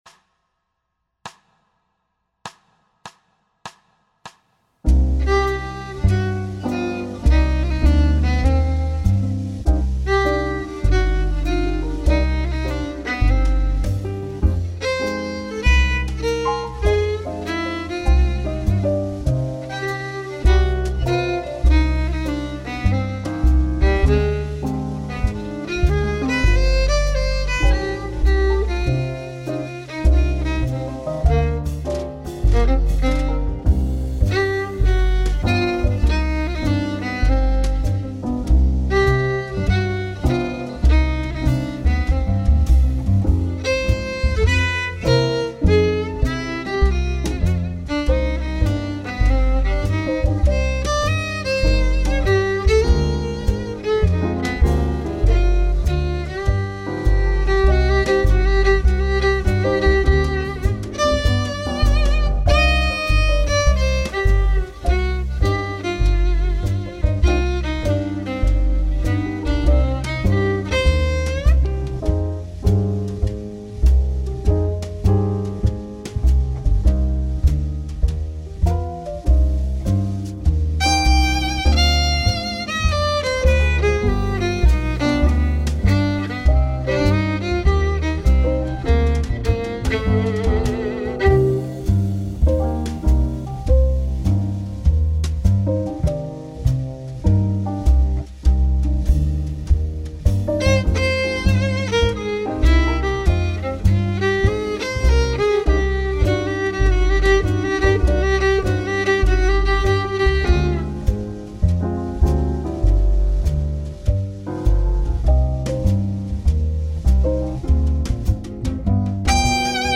practice track
medium blues in C minor